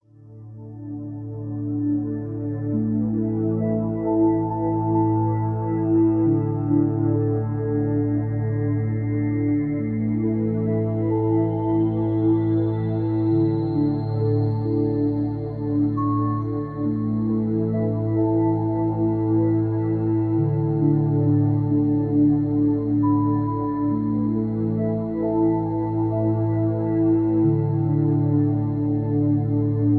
rock, hip hop
backing tracks